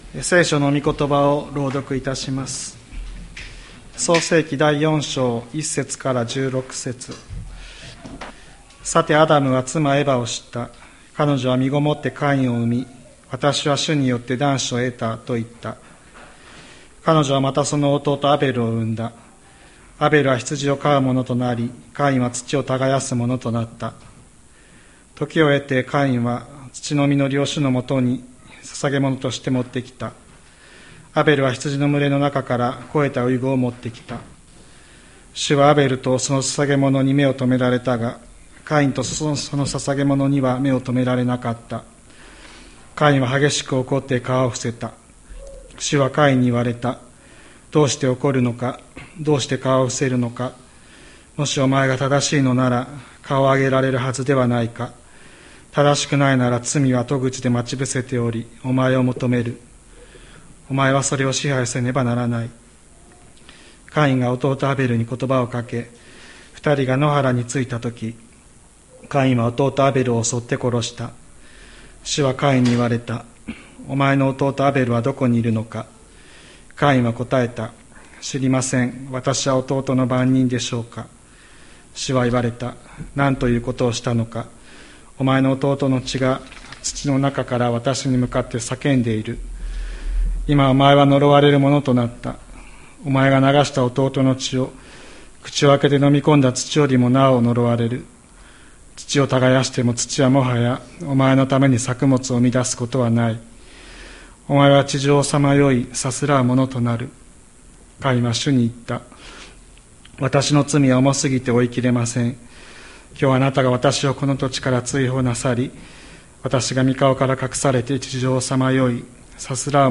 2024年11月17日朝の礼拝「顔を上げよ」吹田市千里山のキリスト教会
千里山教会 2024年11月17日の礼拝メッセージ。